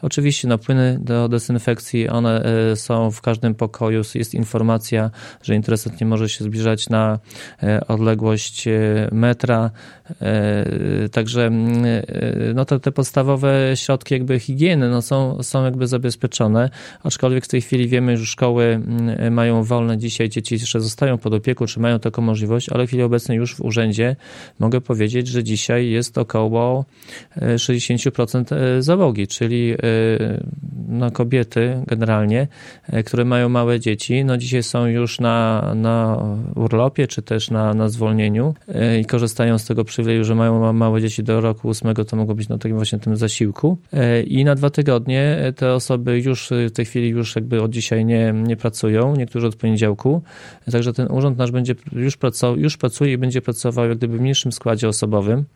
– Odczuwamy już pierwsze skutki epidemii – mówi Zbigniew Mackiewicz, wójt Gminy Suwałki.